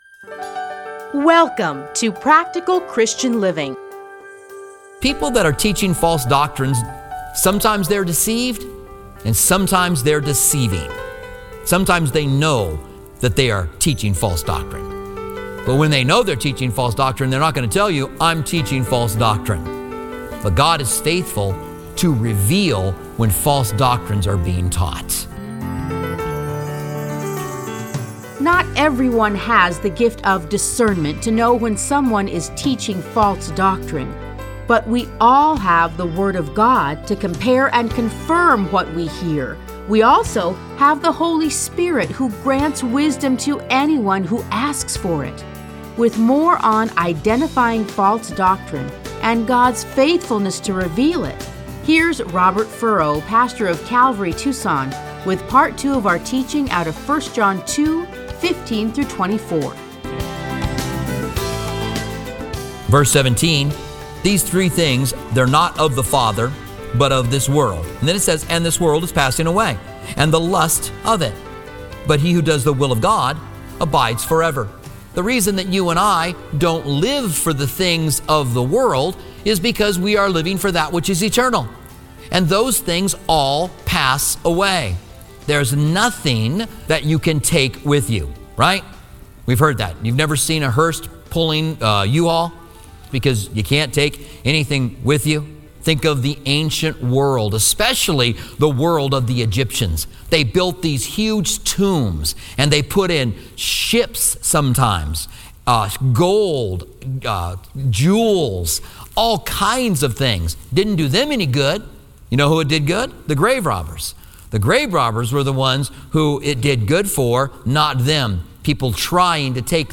Listen to a teaching from 1 John 2:15-24.